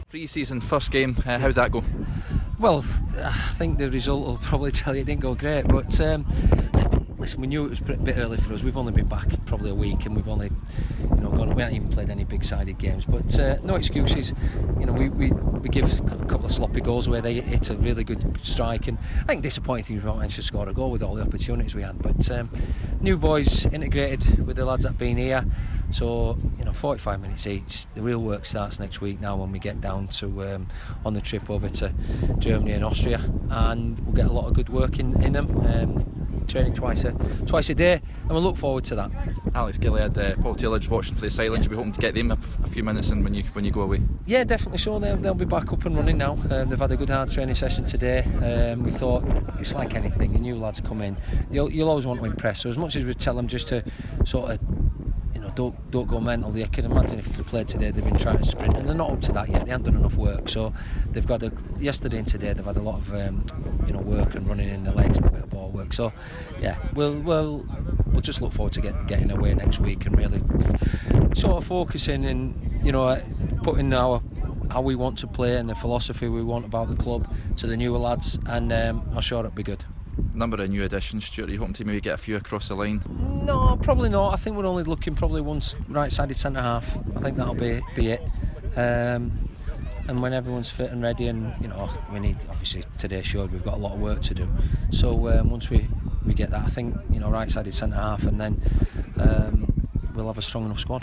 Bradford City boss speaks